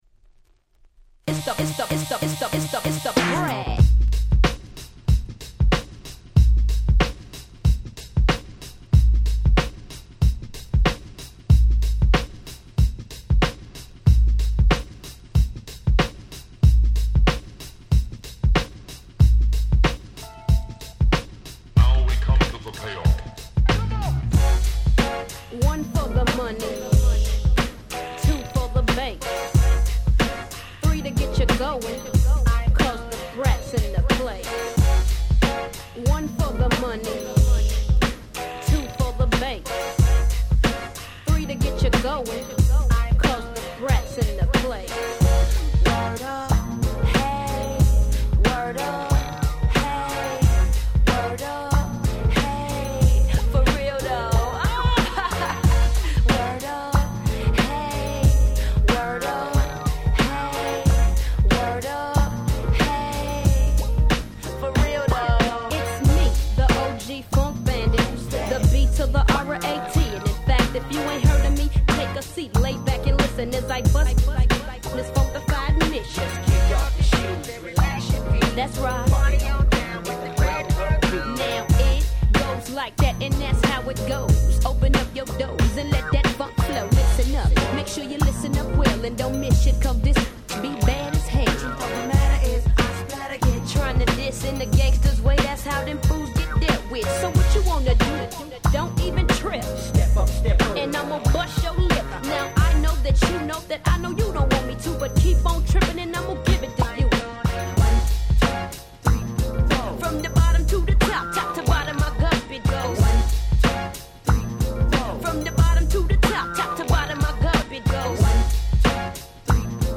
プレーンなIntro付きで非常にMixし易いです！